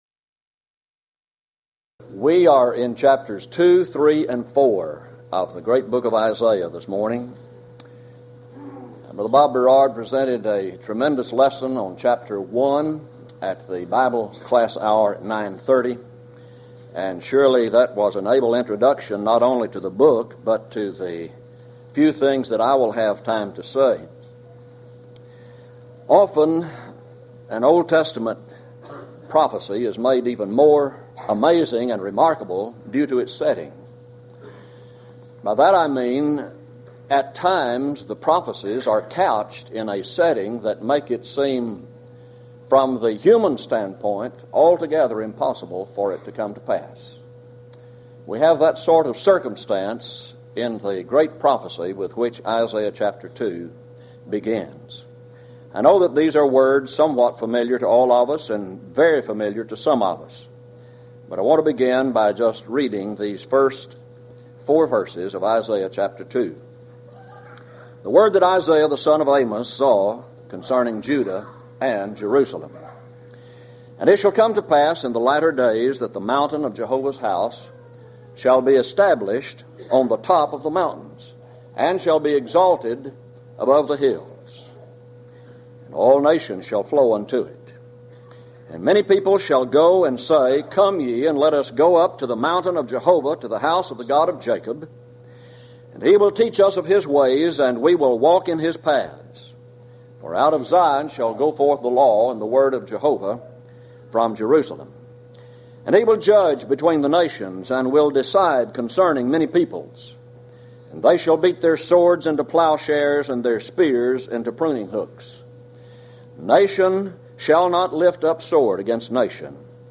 Series: Houston College of the Bible Lectures Event: 1995 HCB Lectures